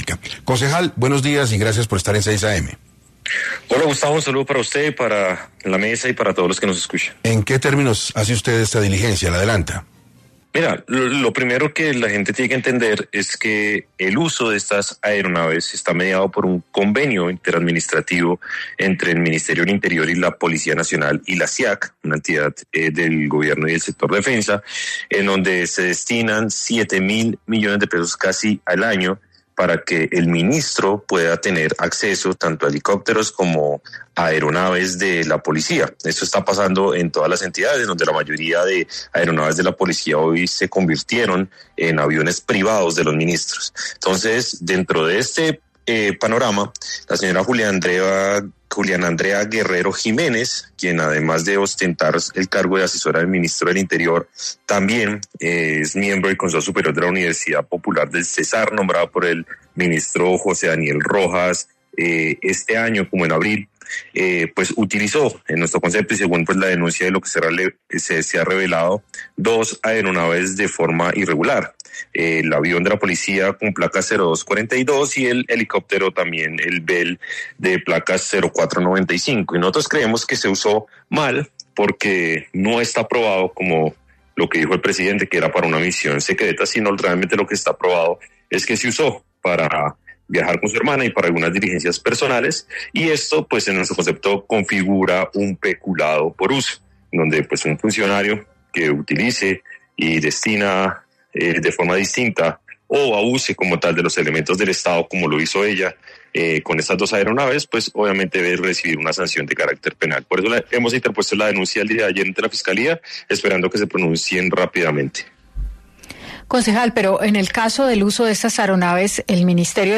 Briceño explicó en entrevista con 6AM de Caracol Radio, que la utilización de estas aeronaves está regulado por un convenio inter administrativo entre el Ministerio del Interior, la Policía Nacional y el Ministerio de Defensa, que destina cerca de 7 mil millones de pesos anuales para que el ministro tenga acceso a estos recursos.